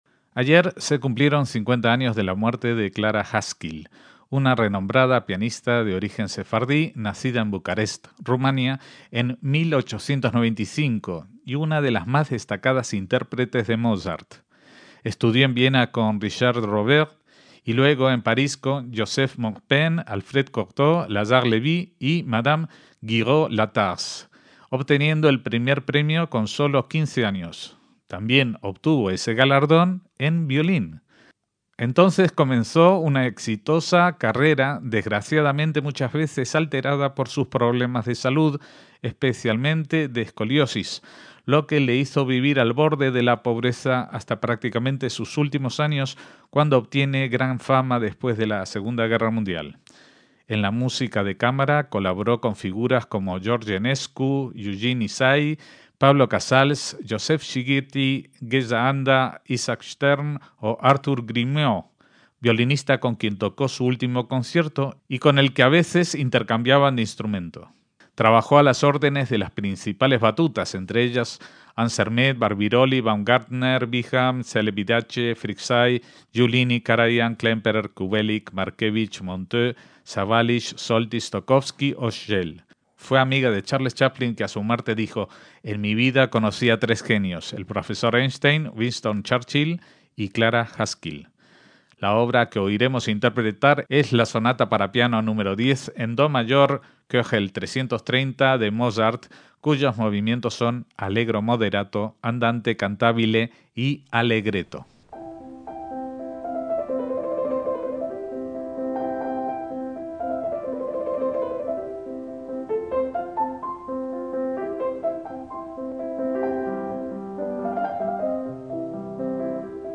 MÚSICA CLÁSICA - A finales de 2010 emitimos originalmente este programa en homenaje a los 50 años del deceso de Clara Haskil, pianista suiza nacida en Bucarest (Rumania) en 1895 y fallecida en 1960, reconocida como experta intérprete del repertorio del clasicismo y del romanticismo temprano y, en concreto, de Mozart, con cuya Sonata Nº 10 en do mayor K.330 para teclado nos deleita en esta grabación.